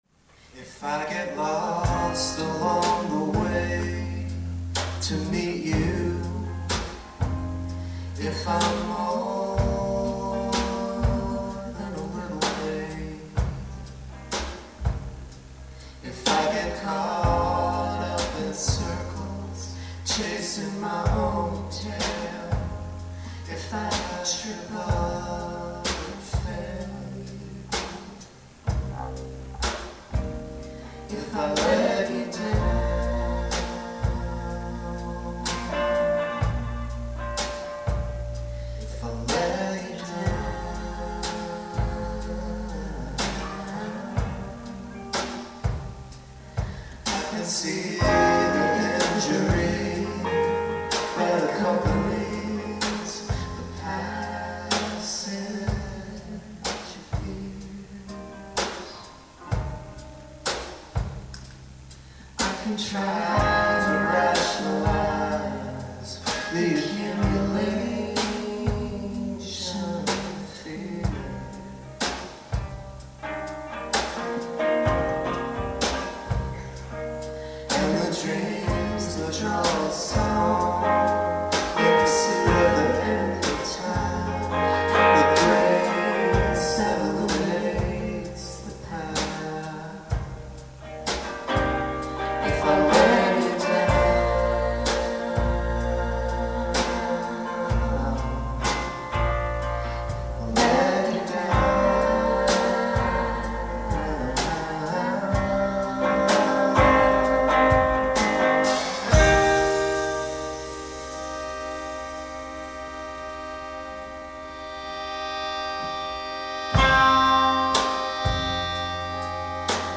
Full set from the MFA Boston’s Remis Auditorium